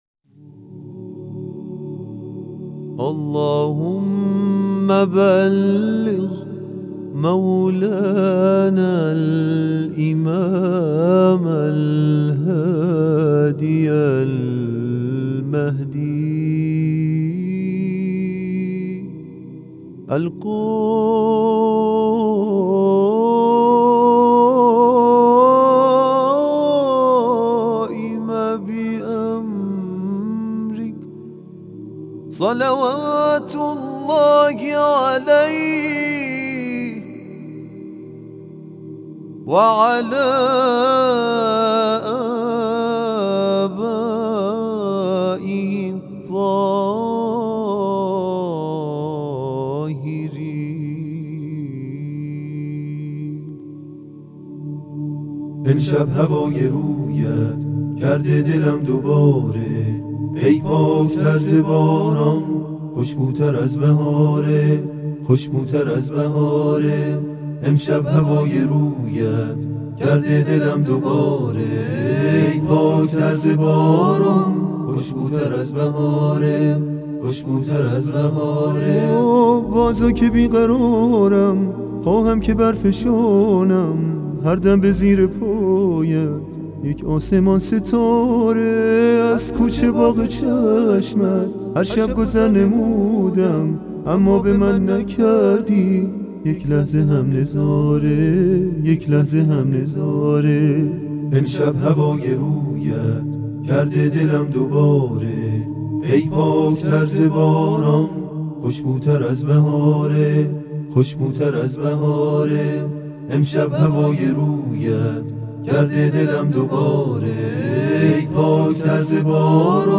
تواشیح